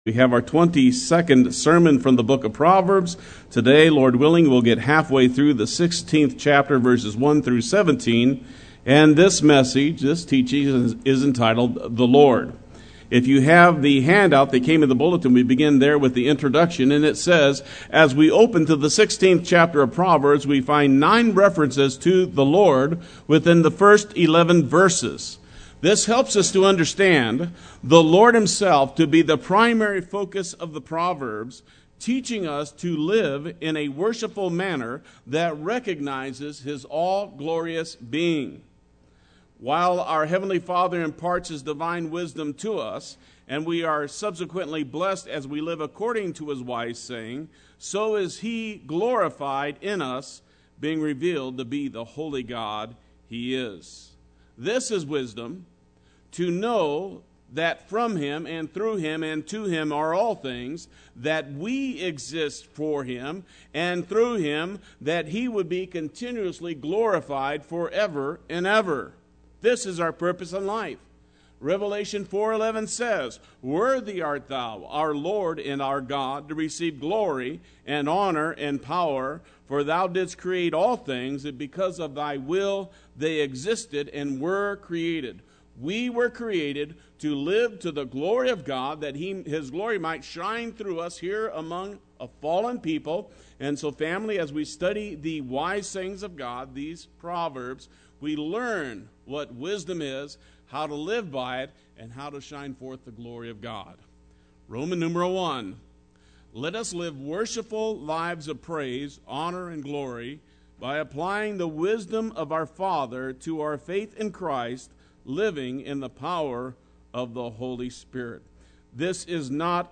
Play Sermon Get HCF Teaching Automatically.
The Lord Sunday Worship